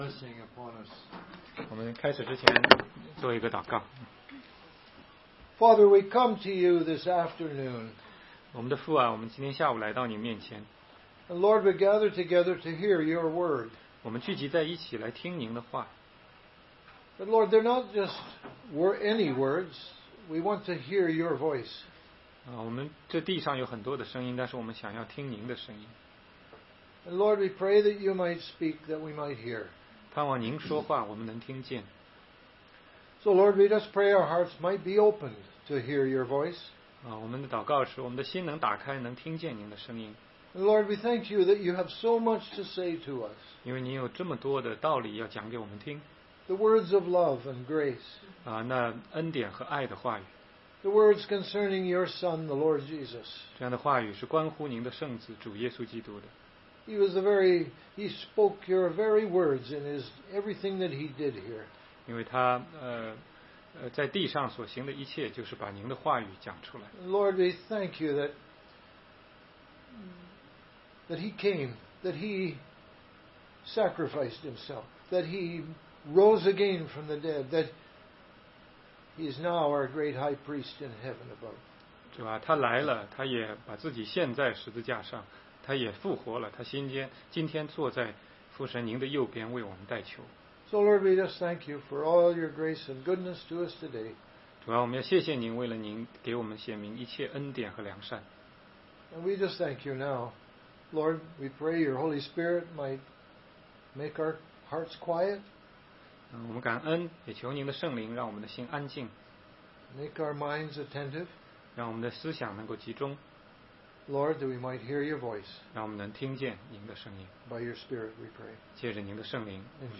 16街讲道录音 - 从米非波设的故事认识神的恩典